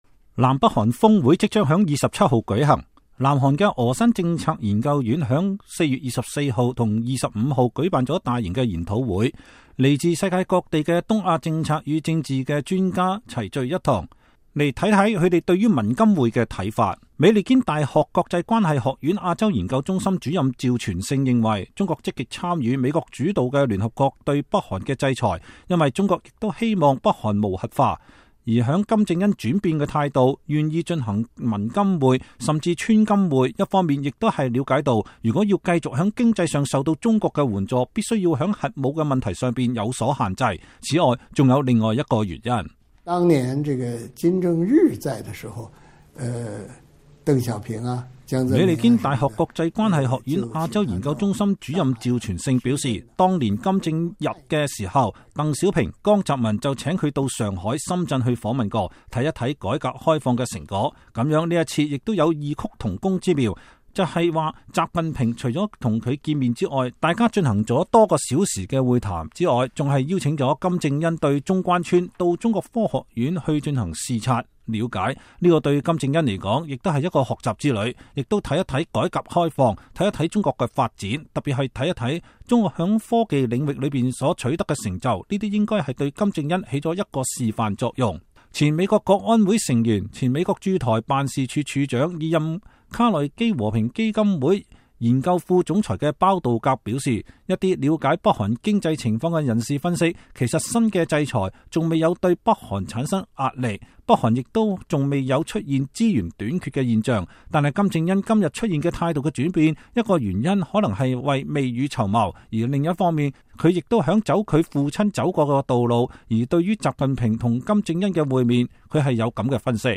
美國之音專訪了幾位專家，來看看他們對於文金會的看法。